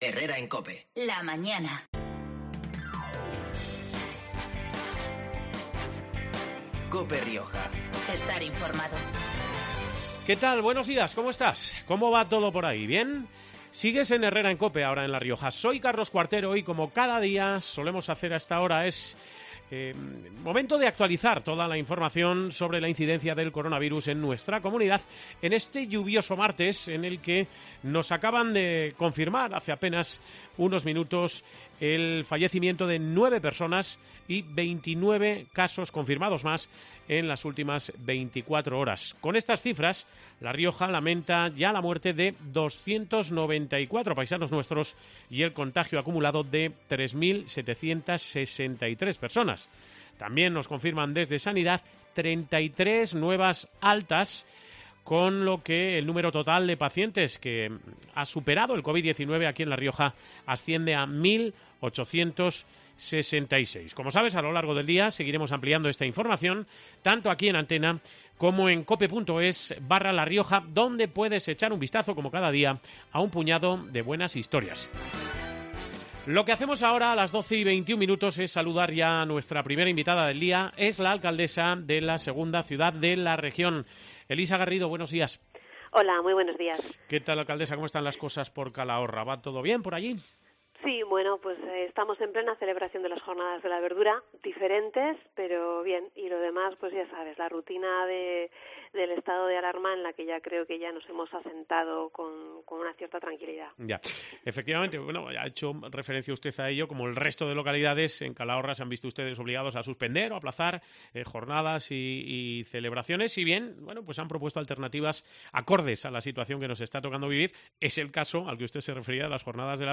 Así al menos lo ha explicado este mediodía en COPE Rioja la alcaldesa Elisa Garrido, que considera que otro tipo de actos son gestos hacia la galería y no motivos de luto y acompañamiento real a esas familias.